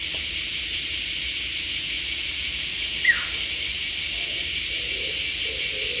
今朝は時々、キュイという声が聞こえていました。
途中、キュウゥと鳴いてくれました。
昔聞いた声に比べて少し可愛く聞こえたのは幼鳥だったからでしょうか。
ササゴイの声はココ（６秒間に１声）